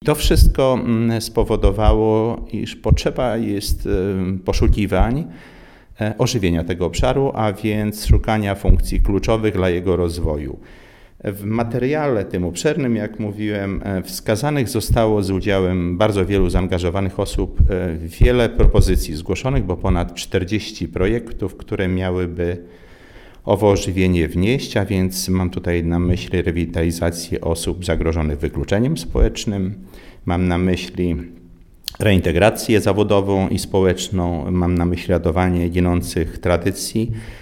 Zdzisław Przełomiec, przewodniczący Rady Miasta Suwałki zwraca uwagę, że problem jest poważny, bo dotyczy 15 procent mieszkańców.